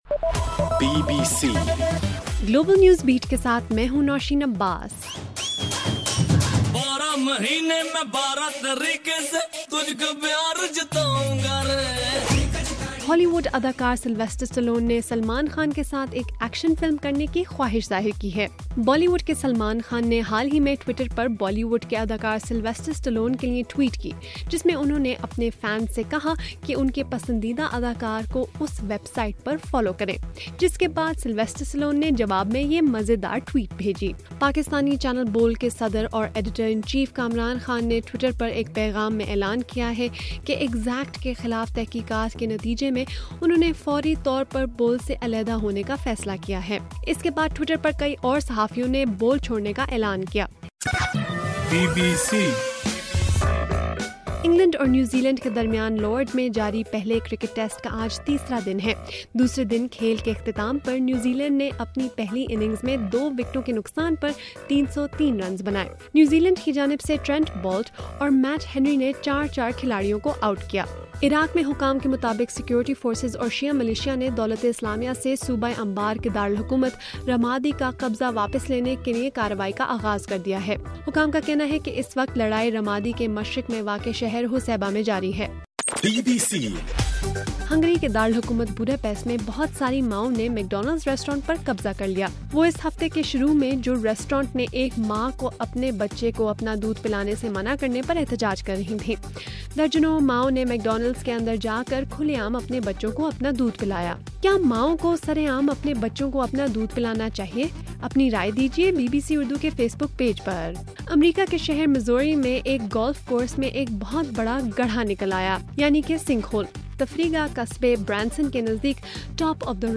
مئی 23: رات 11 بجے کا گلوبل نیوز بیٹ بُلیٹن